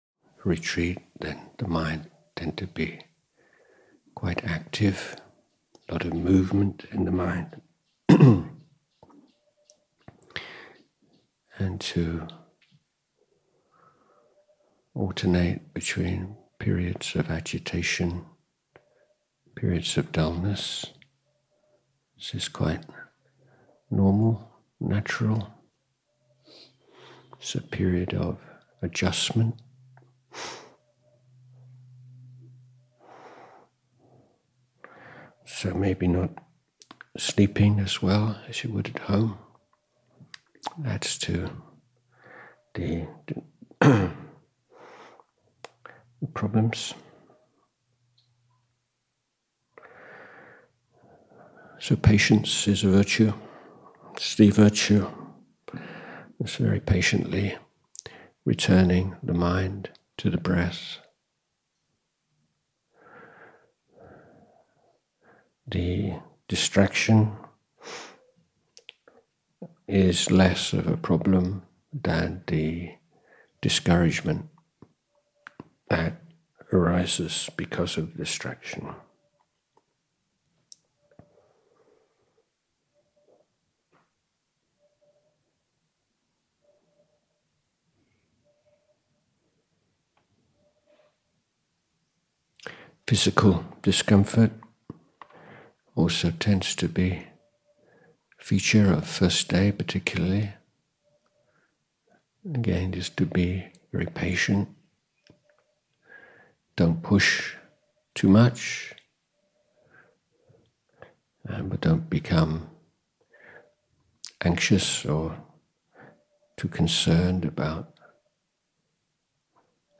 English Retreat at Bahn Boon, Pak Chong, Nakhon Rachasima, 20-24 March 2024